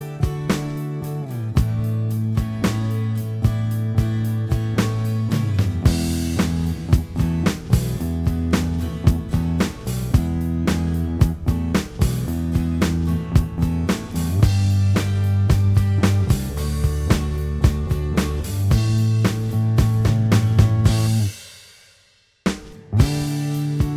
Minus Electric And Solo Guitar Soft Rock 3:08 Buy £1.50